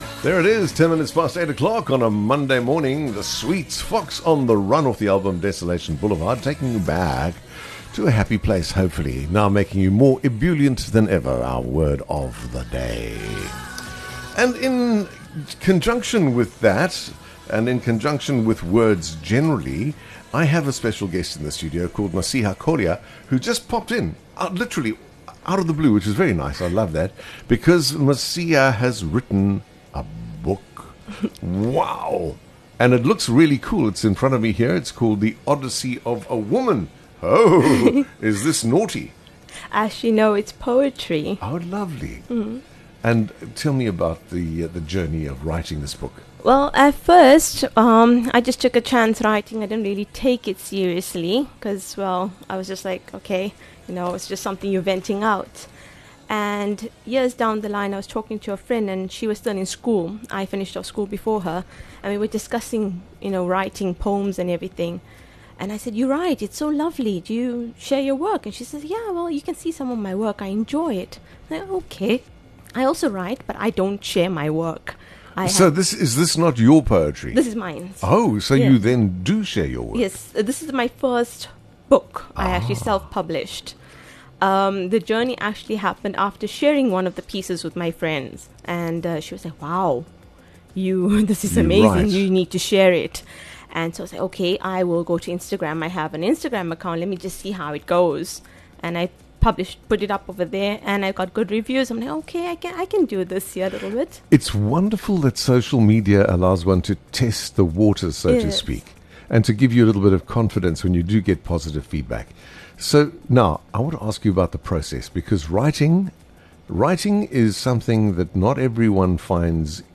Tune in to her interview to hear more about it.